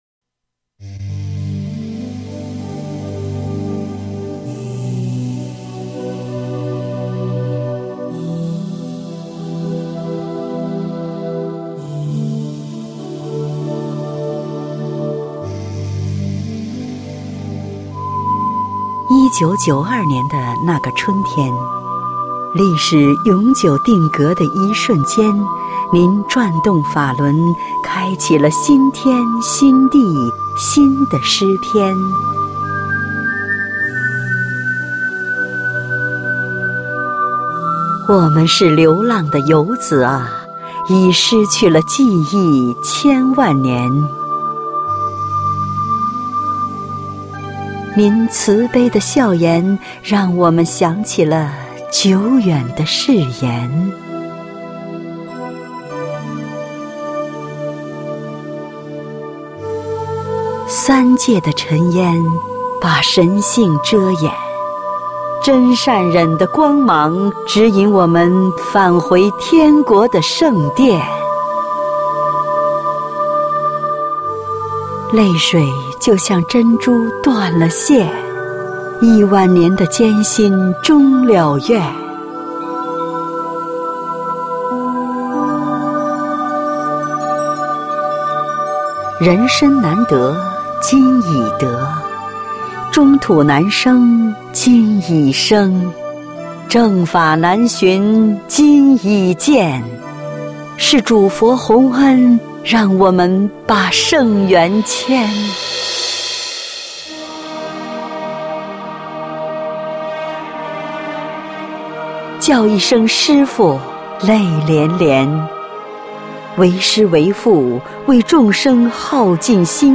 配樂詩朗誦（音頻）：頌師尊